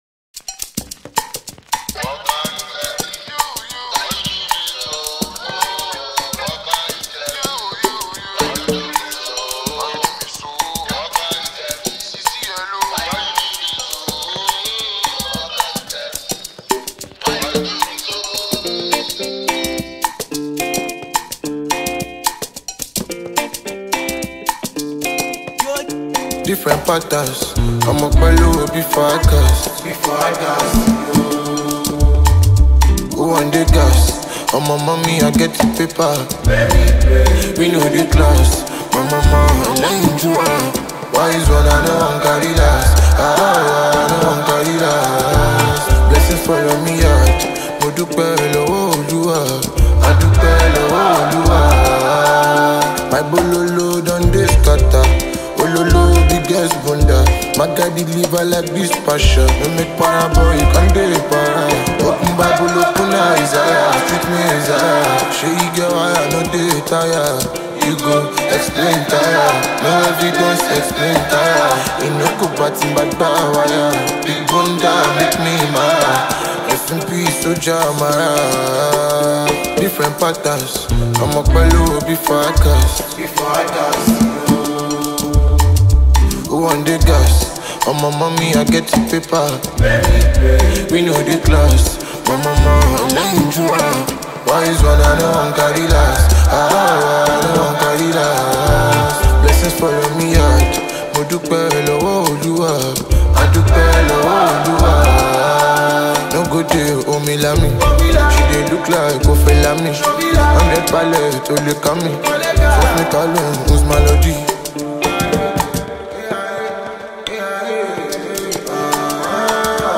AfroPop